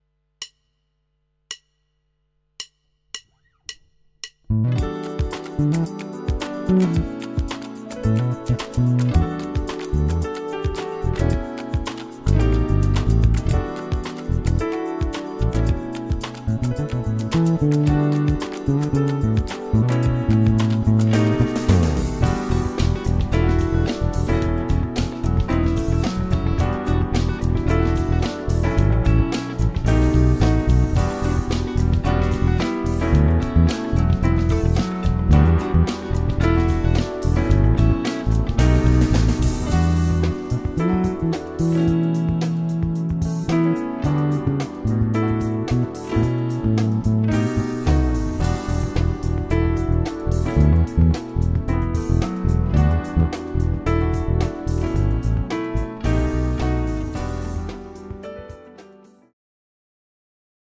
Krkovy snimac